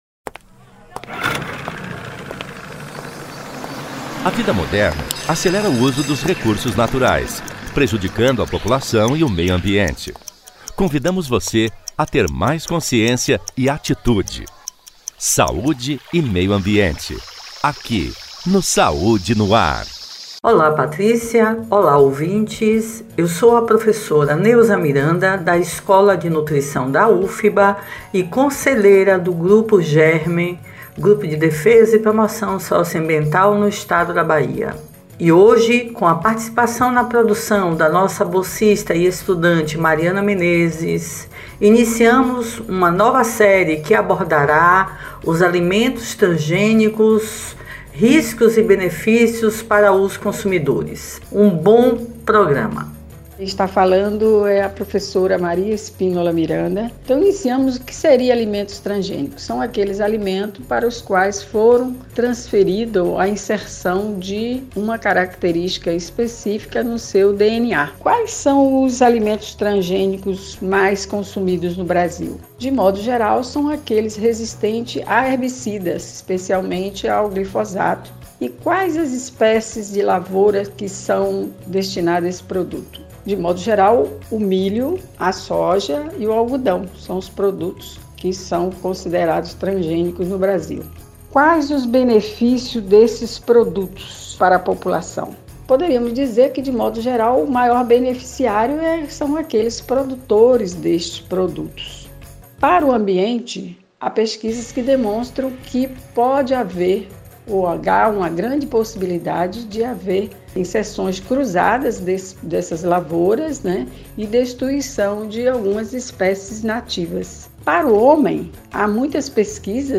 O assunto foi tema do quadro “Meio Ambiente e Saúde”, veiculado às quartas-feiras pelo programa Saúde no ar, com transmissão pelas Rádios Excelsior AM 840  e Web Saúde no ar.